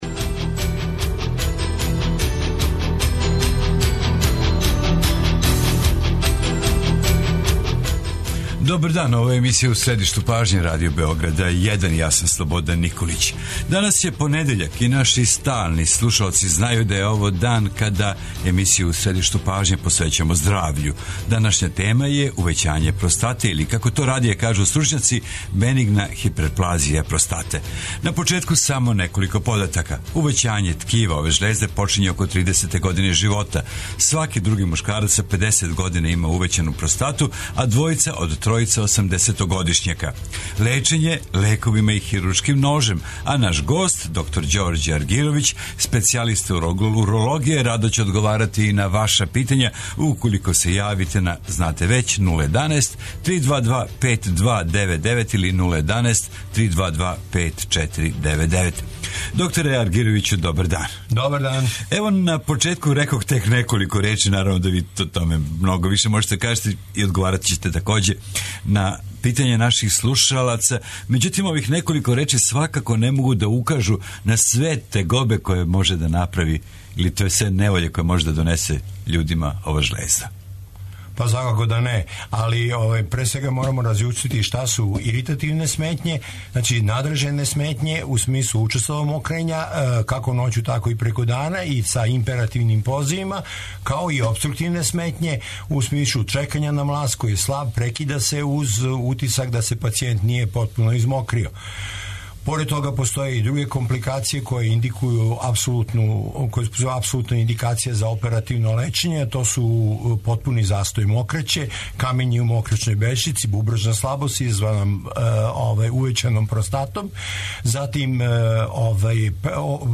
Тема здравствене трибине емисије У средишту пажње је увећање простате или бенигна простатична хиперплазија (БПХ).